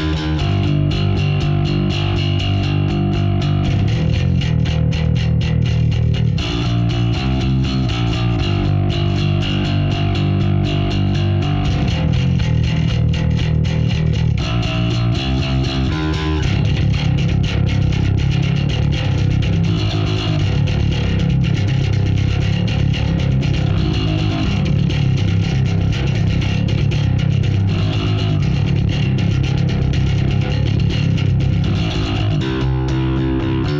Here's a wee snippet of one of my bass profiles...
it's a profile of the M-Pulse, no post EQ or anything, just one track:
(just the raw wav!)
KPABassGrind.wav